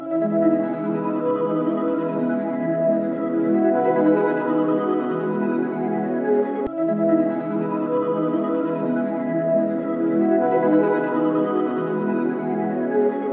NAV PROBLEMS BPM 144.wav